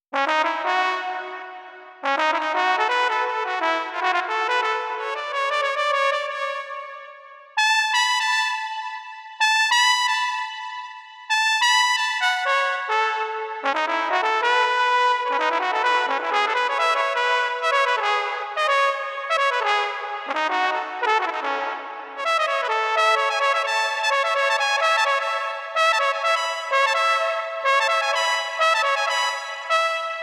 trombone_saxo_01.wav